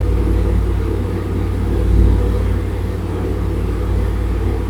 EVP Clip 10 Villisca Axe Murder House EVP Clip 10 This fascinating EVP of a child singing was recorded on a digital recorder (Zoom H4N) that I had placed on the 3rd step leading up from the kitchen to the upstairs area. I was in the home all alone when this was recorded, and I did not hear this voice in real-time. I've amplified this file quite a bit, and removed some of the background "hiss" to improve the voice's clarity. This is a faint, high-pitched girl's voice, but if you give this several listens I believe you'll start to be able to hear it and maybe even pick out some words.
Child sings line from a song This sounds to me like a child singing a line from a church song.